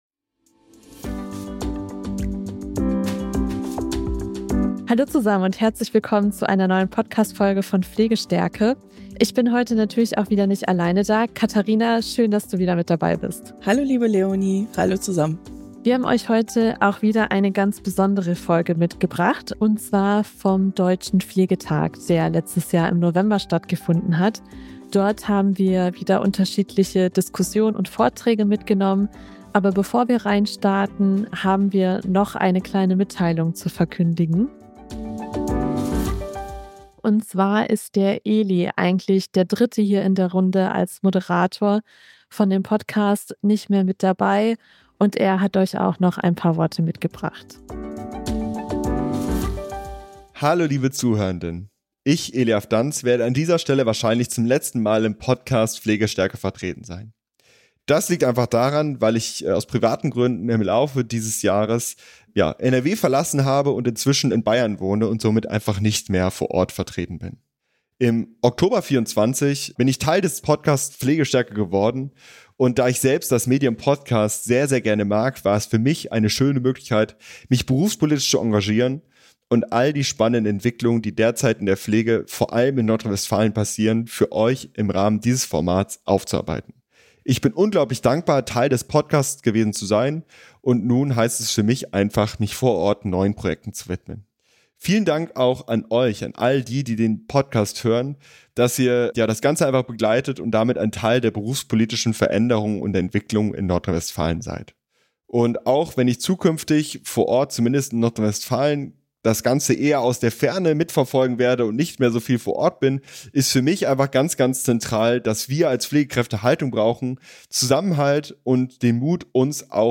Genau dieser Frage widmet sich unsere Sonderfolge vom Deutschen Pflegetag 2025. Im Zentrum steht das Thema autonome Heilkunde und die Frage, wie Pflegefachpersonen endlich das tun dürfen, was sie längst kompetent leisten.
Wie dieser Wandel konkret aussehen kann, diskutieren Vertreter*innen aus Politik, Wissenschaft und Praxis. Sie machen deutlich, warum der Arztvorbehalt in vielen Situationen Versorgung behindert, weshalb pflegerische Diagnosen zentral sind und welche Rolle Selbstverwaltung und Pflegekammer für eine klare berufliche Positionierung spielen.